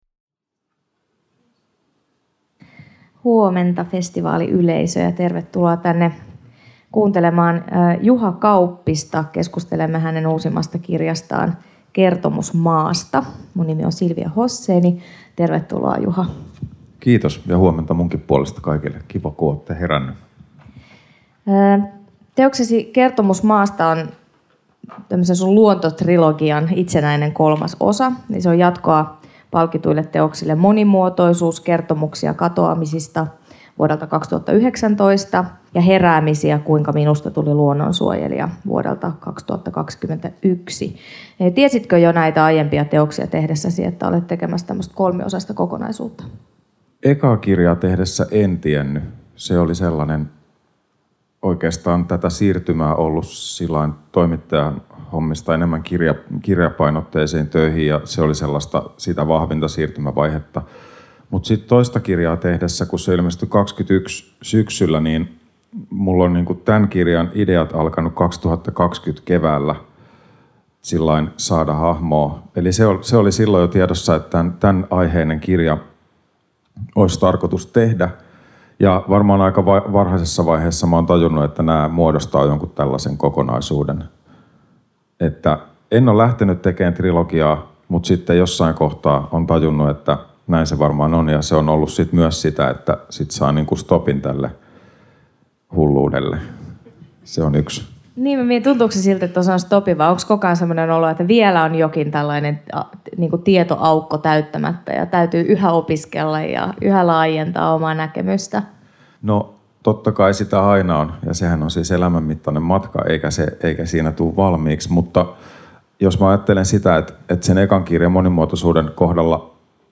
Tampereen Kirjafestareilla